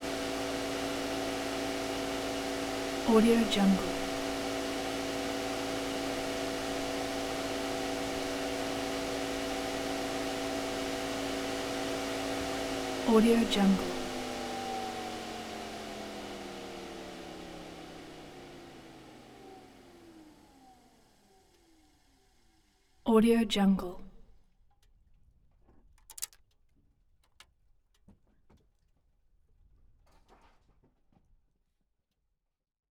دانلود افکت صوتی شهری
دانلود افکت صوتی صدای خاموش شدن موتور